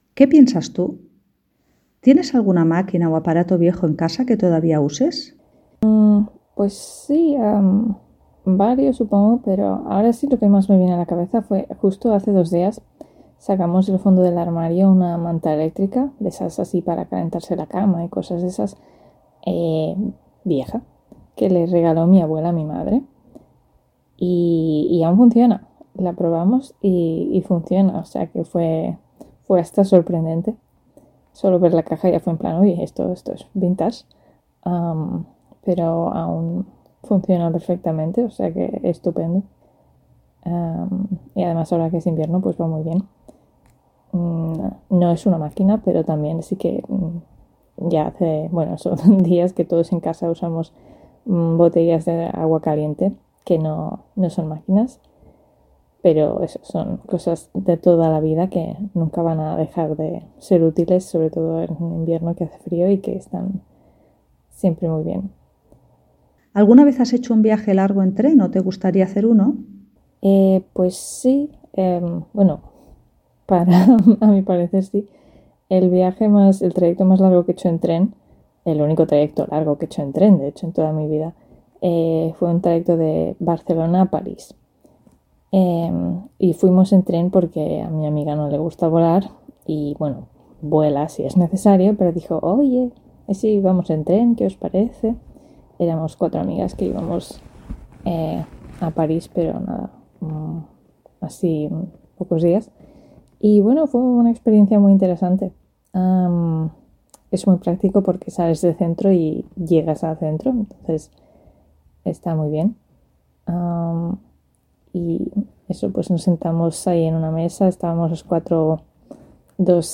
Ahora escucha a un nativo que contesta las preguntas.